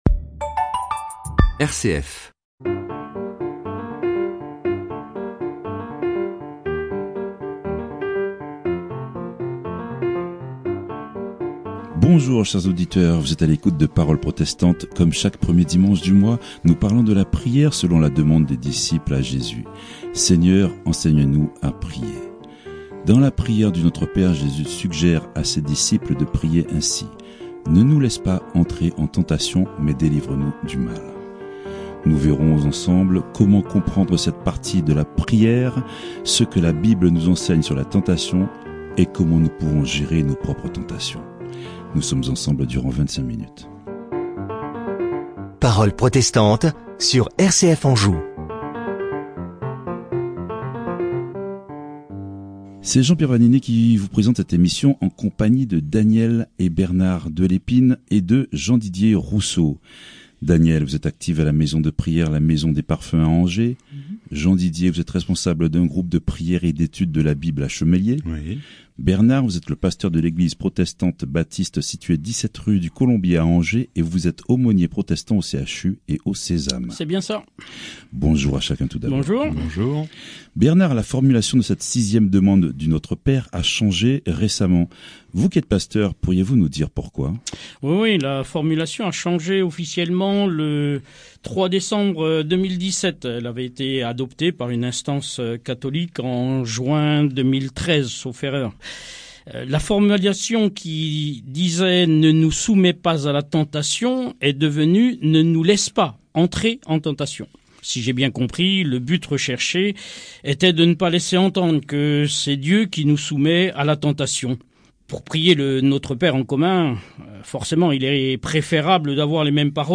Séries : Emission de radio RCF | Ne nous laisse pas entrer en tentation mais délivre-nous du mal ».